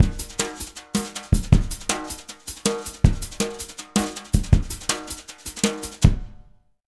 Index of /90_sSampleCDs/USB Soundscan vol.08 - Jazz Latin Drumloops [AKAI] 1CD/Partition A/06-160JUNGLB
160JUNGLE8-R.wav